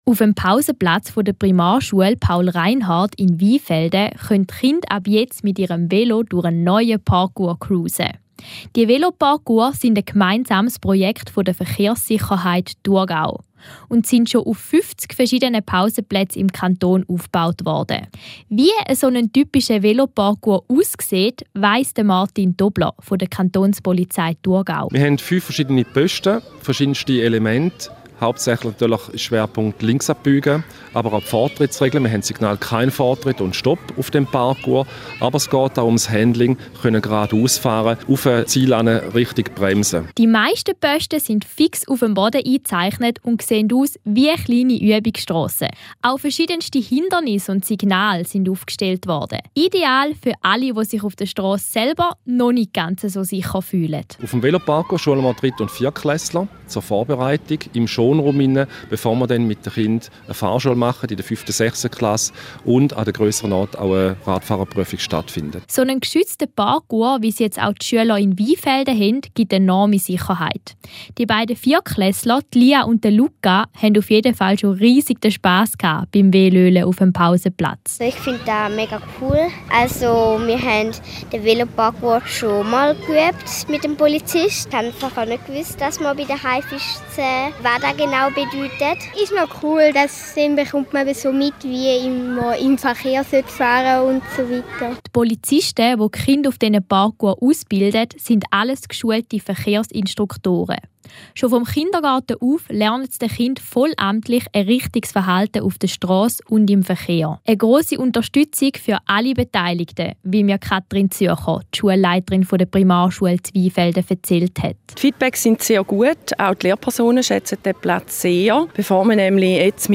Wir waren heute 23.08.2021 bei der jubilaren Eröffnungsfeier des 50. Veloparcours in Weinfelden dabei.